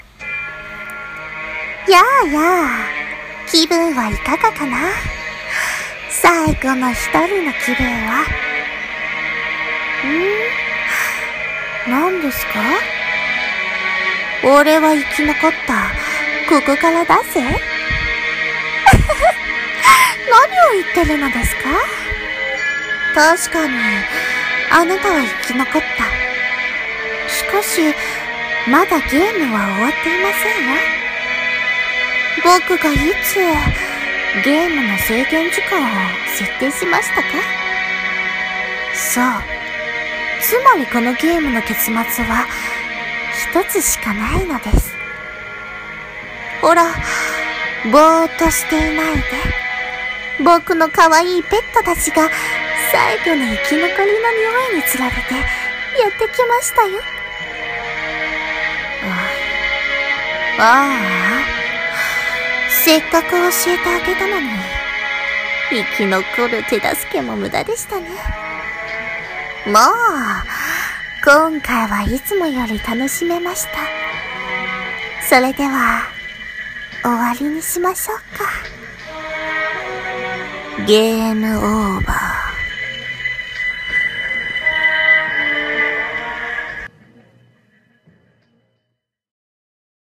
朗読.セリフ【人間ゲーム.終】声劇